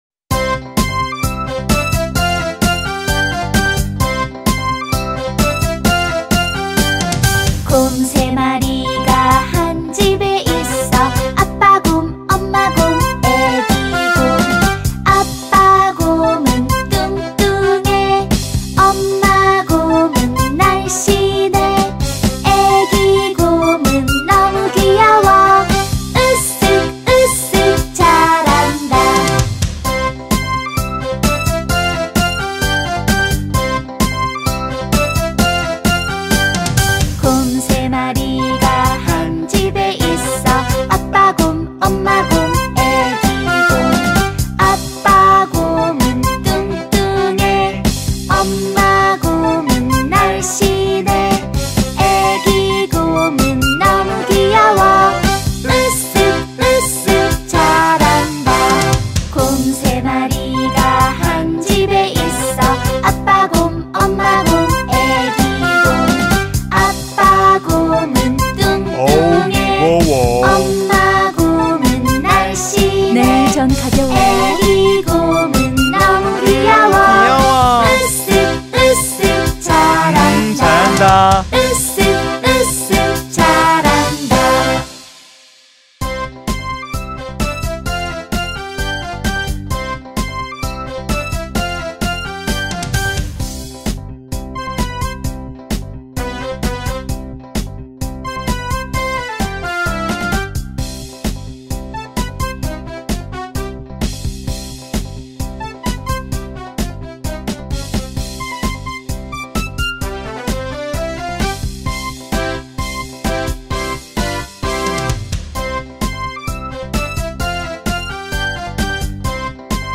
Angklung
Popular Korean Children Song